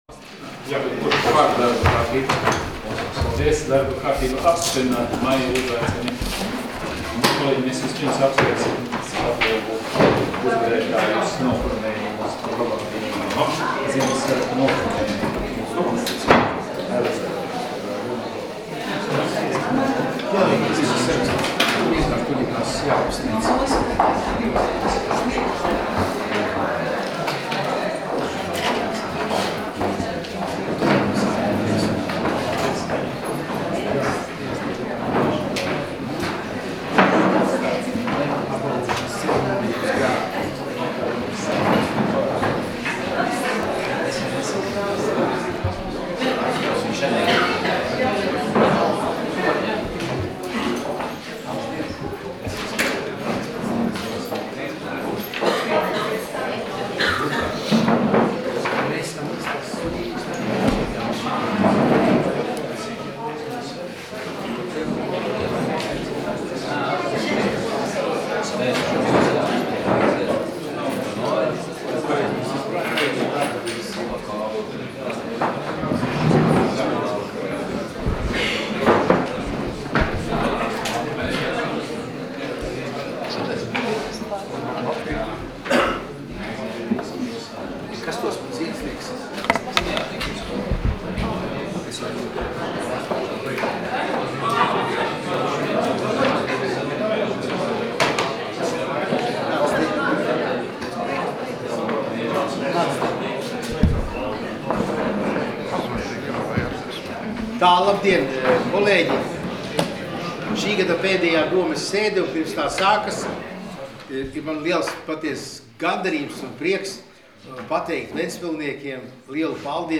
Domes sēdes 28.12.2016. audioieraksts